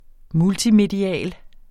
Udtale [ ˈmultimediˌæˀl ]